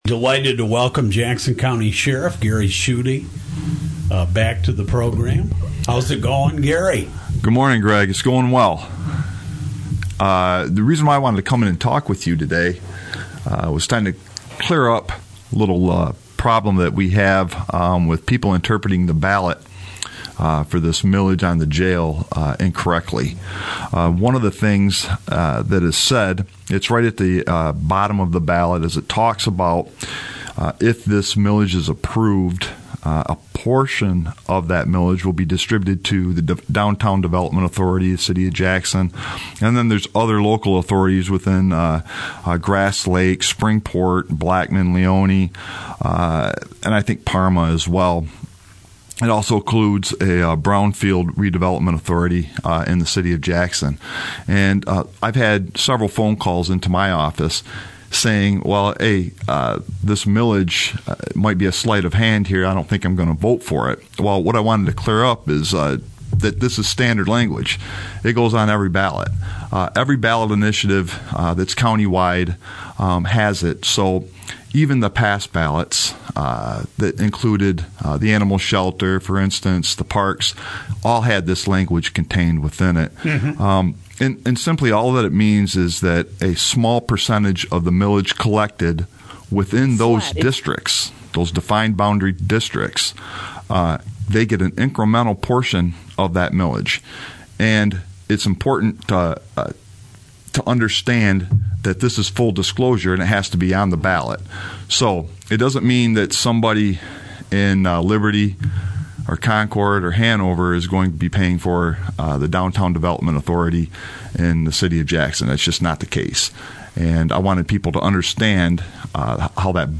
Jackson, Mich. — In a recent interview with WKHM, Jackson County Sheriff Gary Schuette addressed some concerns he has heard from voters regarding the language on the November 8 ballot for the County Jail and Sheriff’s Office.